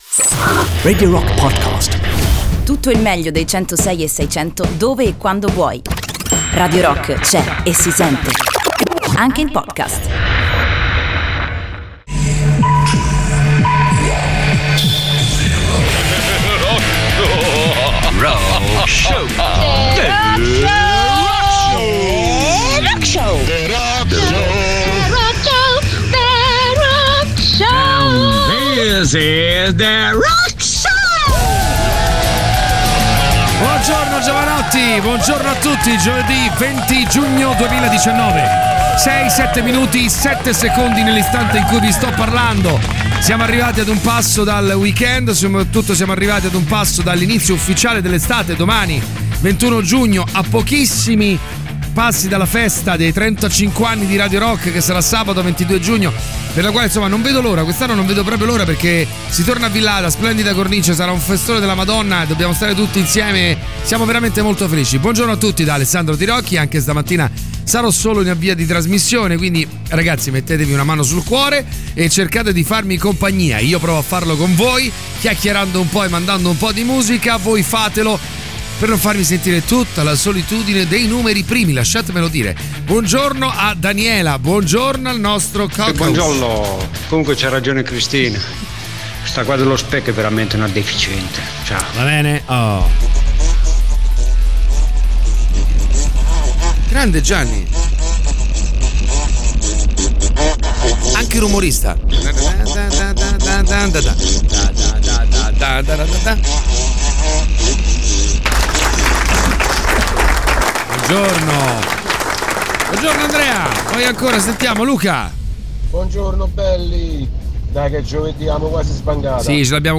in diretta dalle 06.00 alle 08.00 dal Lunedì al Venerdì sui 106.6 di Radio Rock.